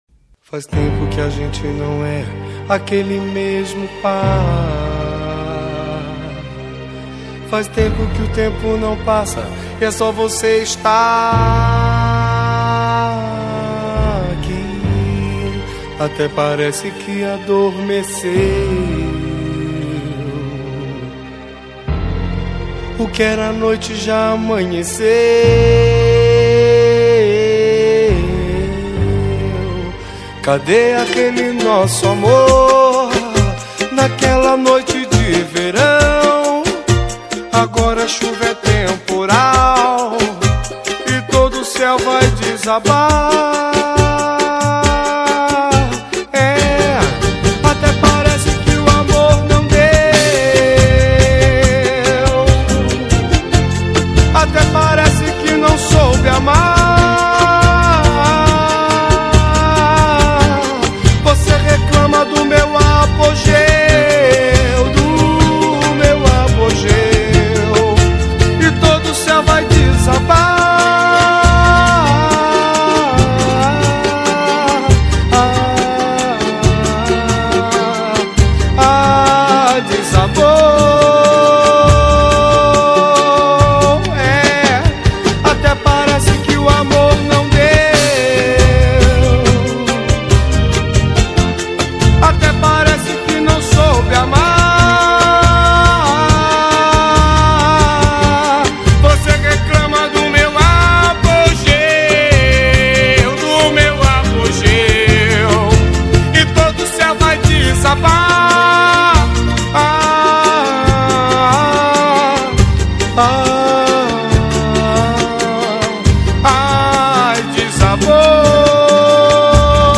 2024-10-09 13:44:30 Gênero: Pagode Views